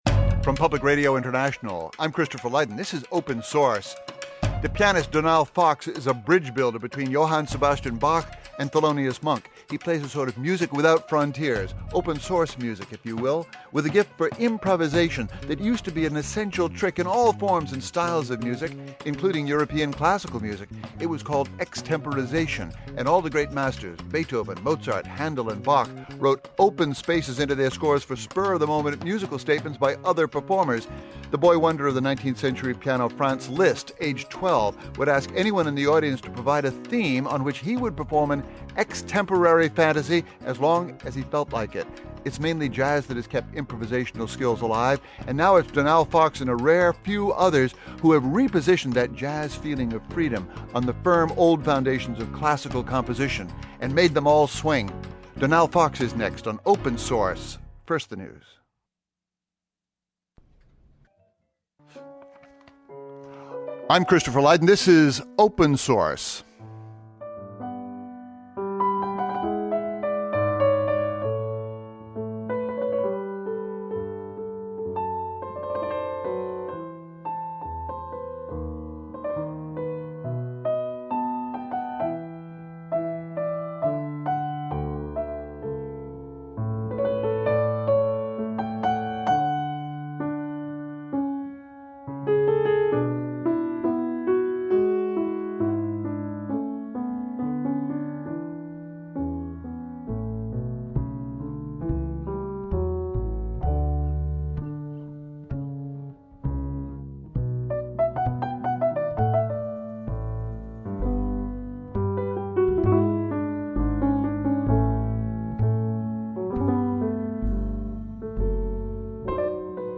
performs live and discusses his unique approach to interpreting Bach, Monk and other musics